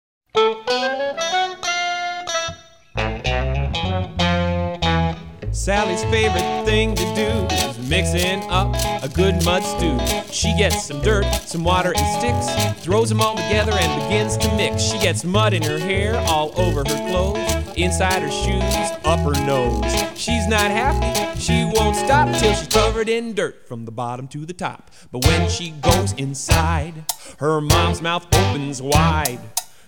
samba reggae and jug-band performances